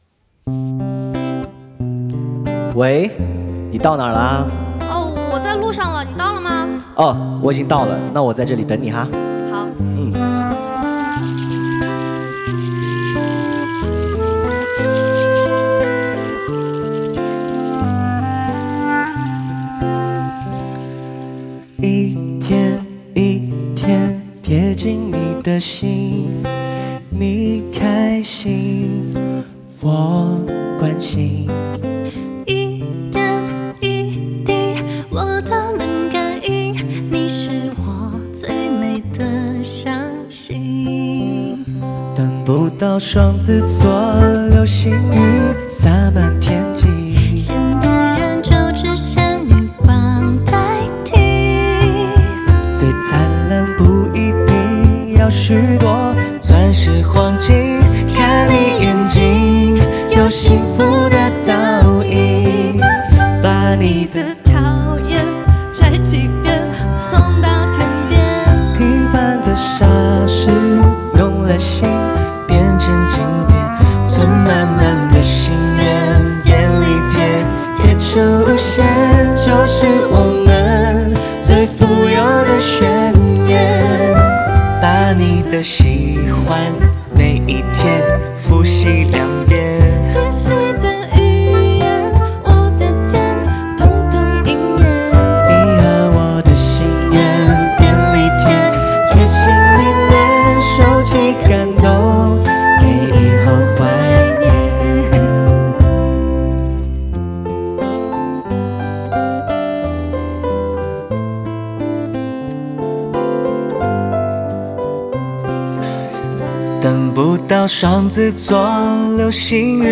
Ps：在线试听为压缩音质节选，体验无损音质请下载完整版
单簧管
吉他
贝斯
键盘
Program/鼓